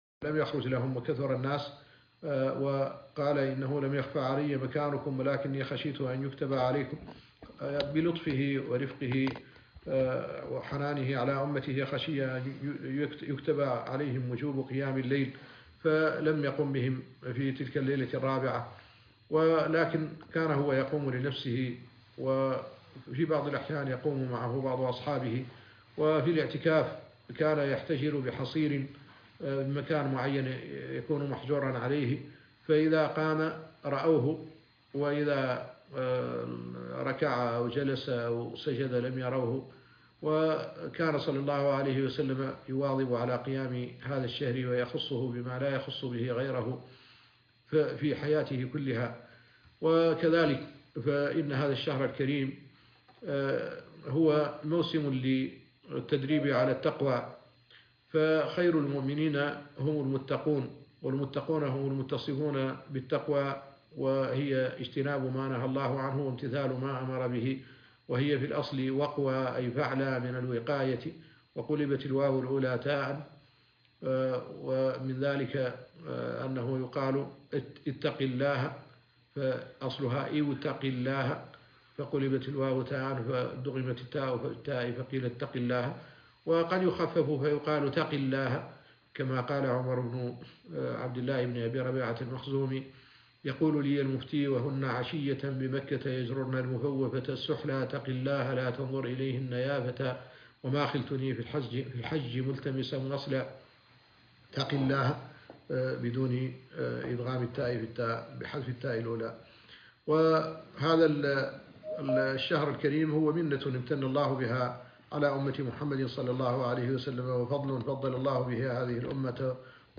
نسائم رمضان محاضرة